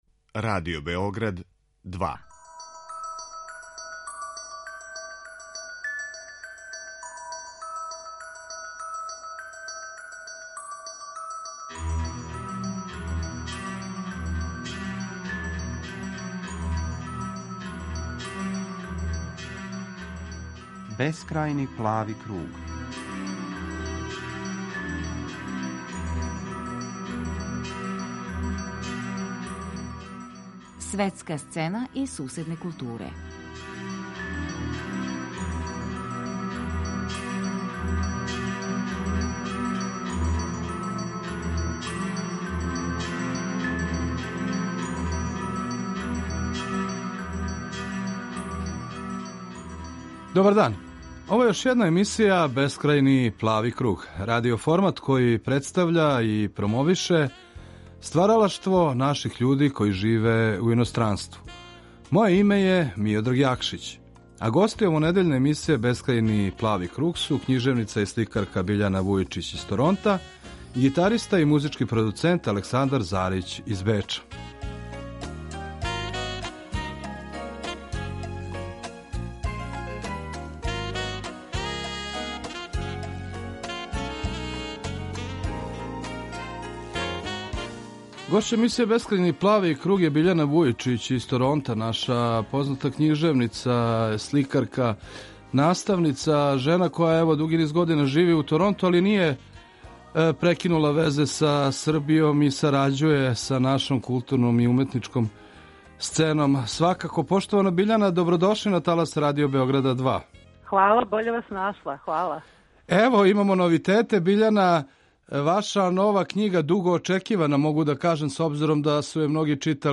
Гости